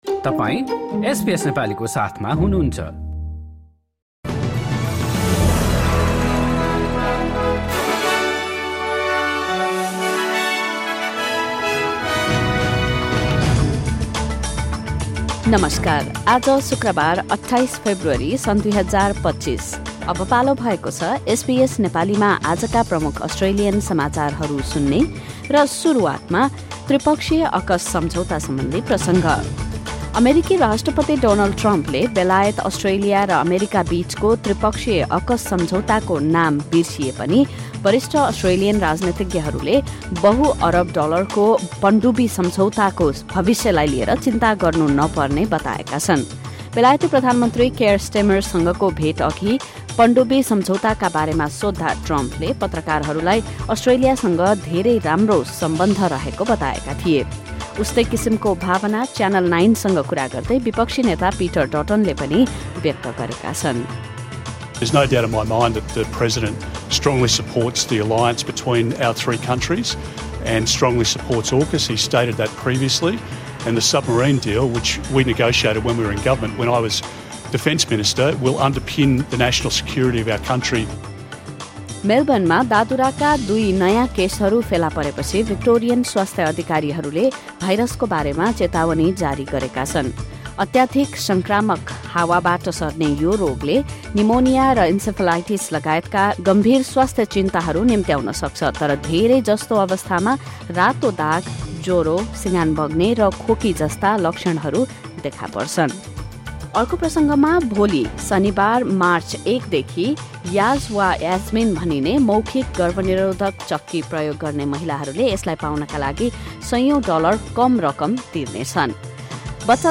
SBS Nepali Australian News Headlines: Friday, 28 February 2025